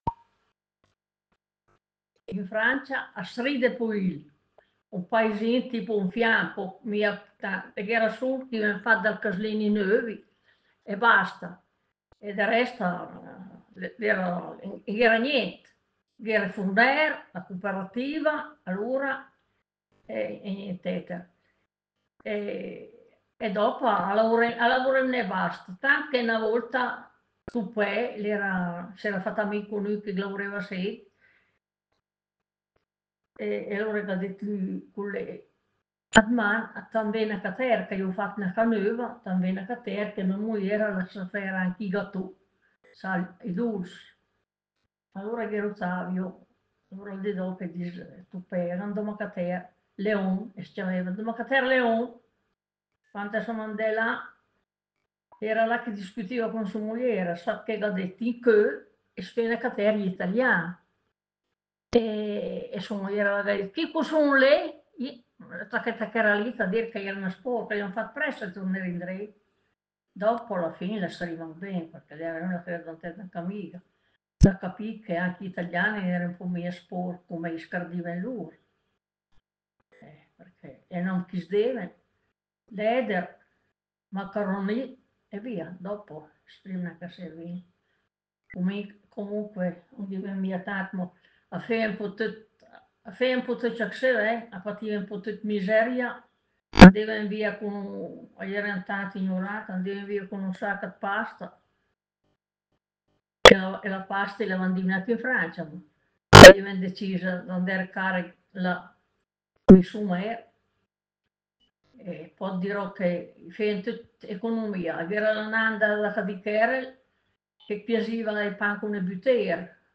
Tuttavia, in seconda istanza, abbiamo anche sfruttato le molte conoscenze dirette che abbiamo, come gruppo di Léngua Mêdra, con persone parlanti dialetti ben caratterizzati.